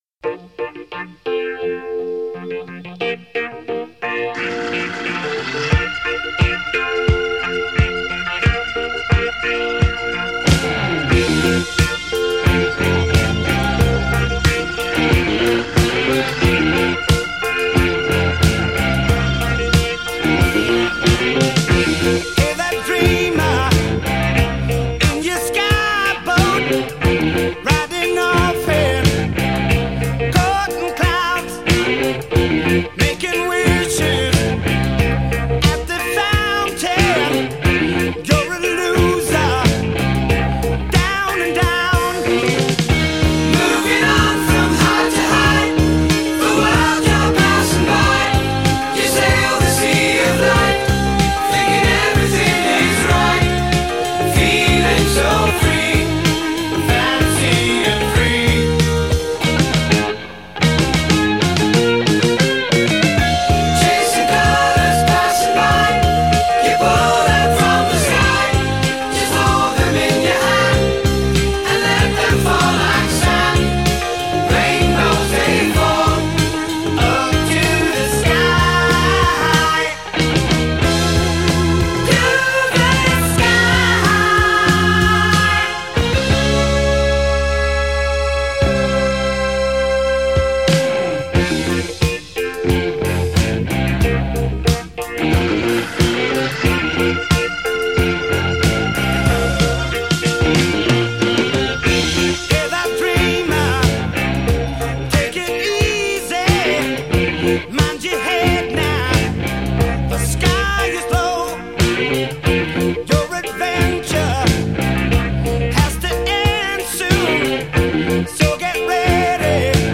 Listen for them here in the middle and end of the chorus.